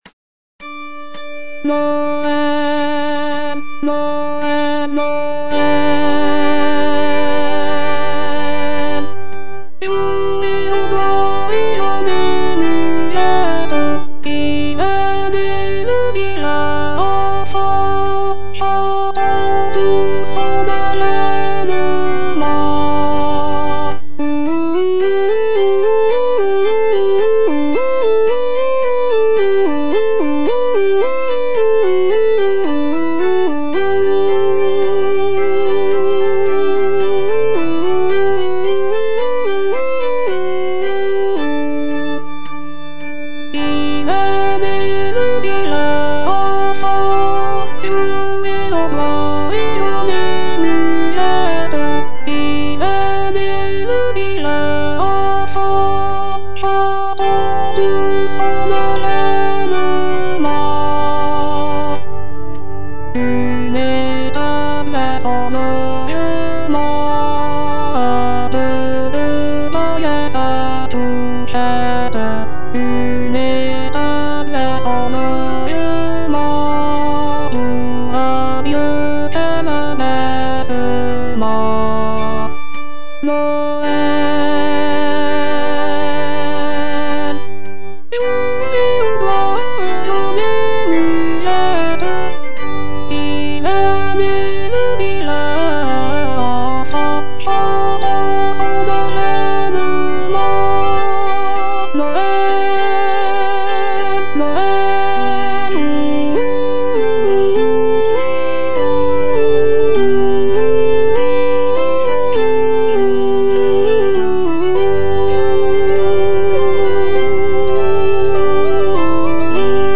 Alto II (Voice with metronome)
traditional French Christmas carol